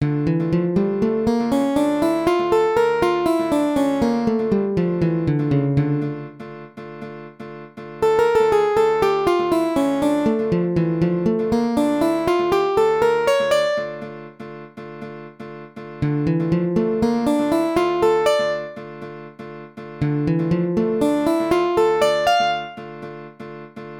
Dmのフレーズ
Dm アルペジオ　フレーズ
Dm-arpeggios-licks.mp3